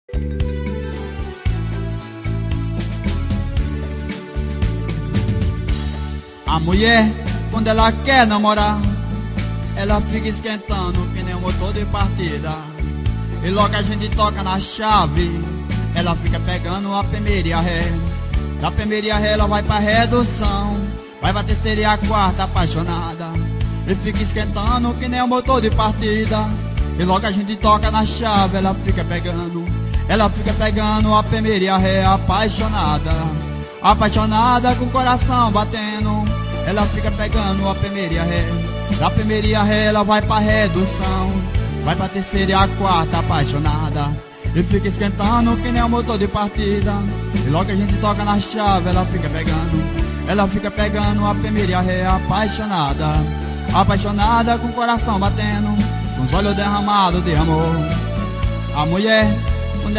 Sertanejo Views